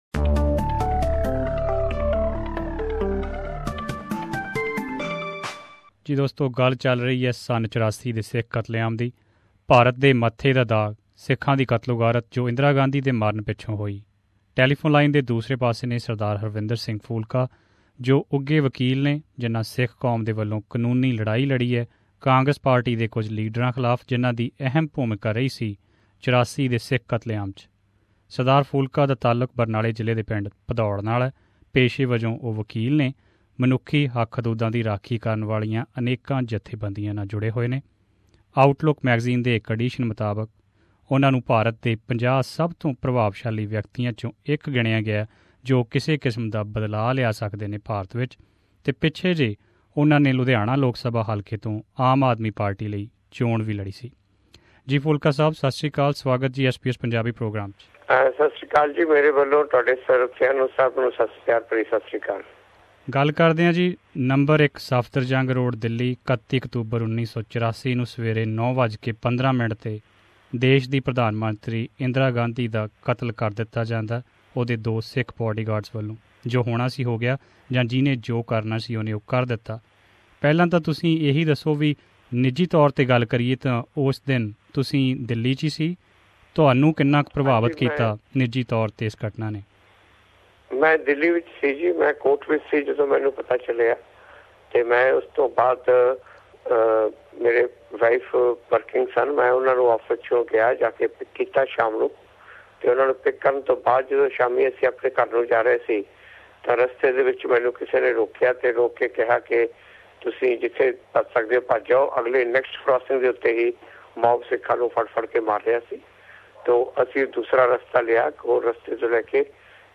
In this interview, we are talking about one of the darkest spots in Indian history - the massacre of Sikhs in the streets of India post-death of India’s then prime minister Indira Gandhi.